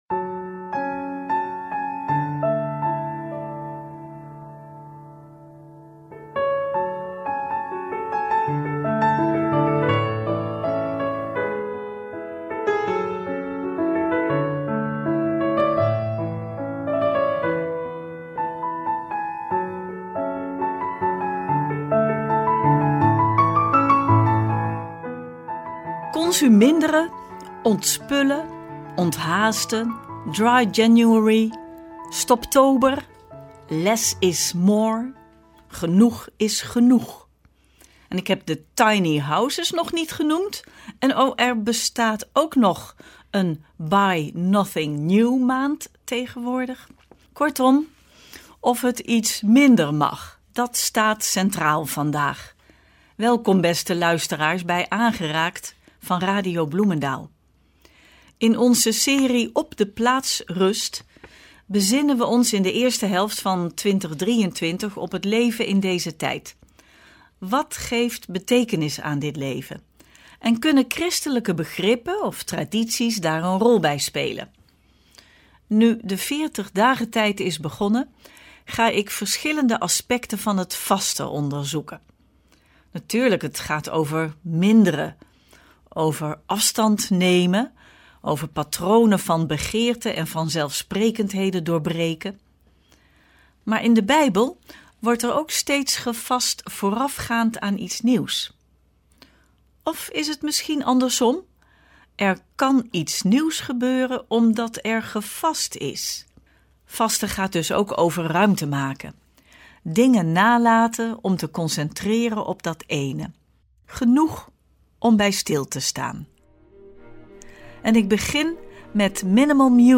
Met muziek die helpt bij het zoeken naar een ander, minder jachtig tempo.